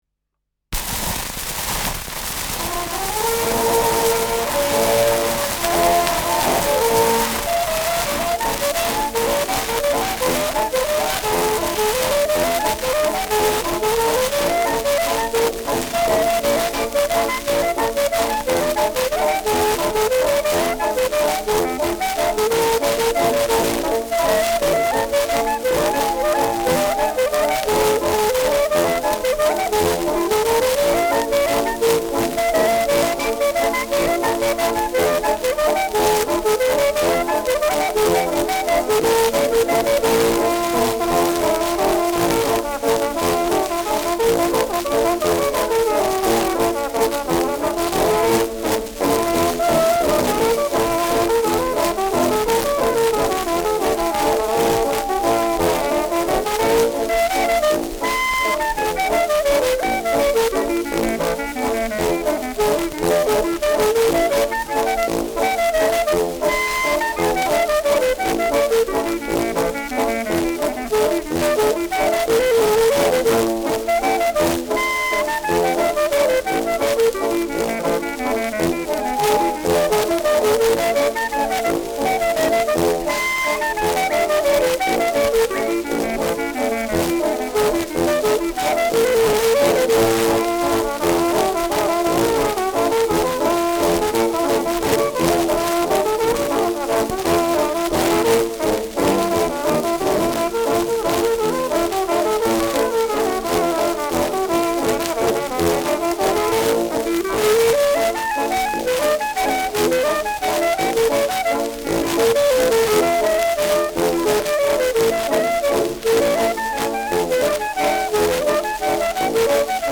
Schellackplatte
starkes Rauschen
Dachauer Bauernkapelle (Interpretation)